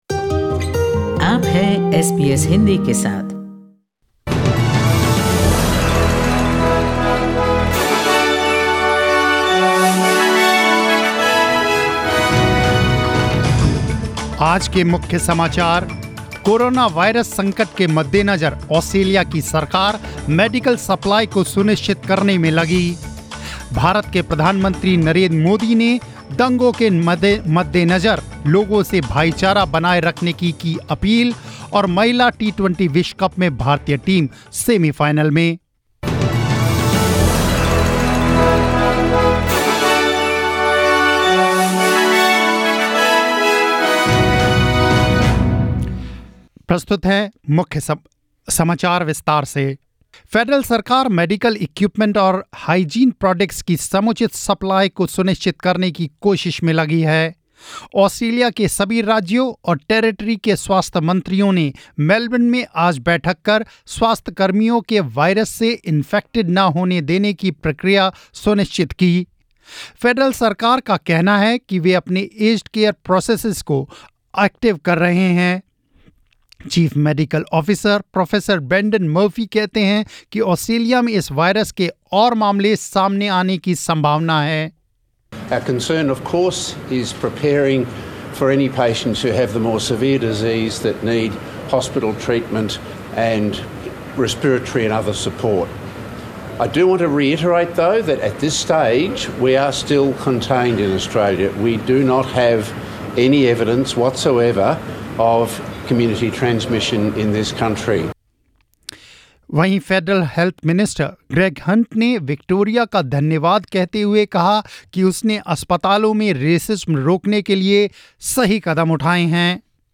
News in Hindi 28 Feb 2020